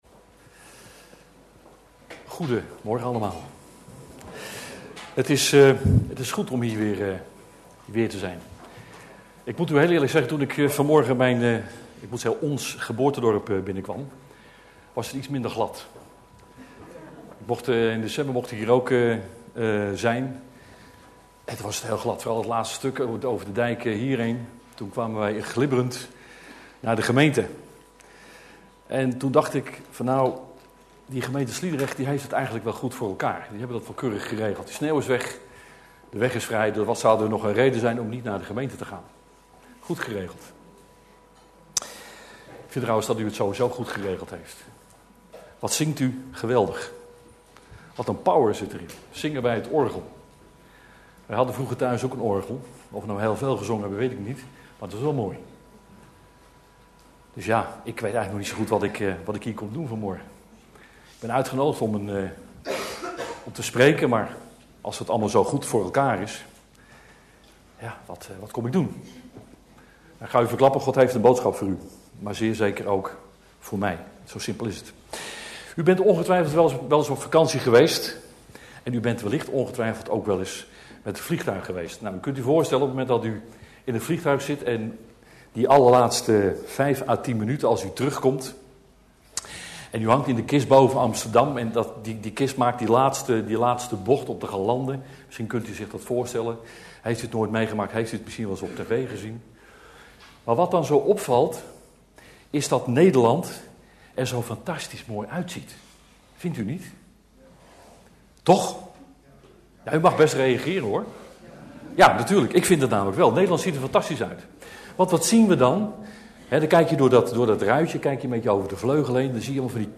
In de preek aangehaalde bijbelteksten (Statenvertaling)Haggai 1:4-74 Is het voor ulieden wel de tijd, dat gij woont in uw gewelfde huizen, en zal dit huis woest zijn?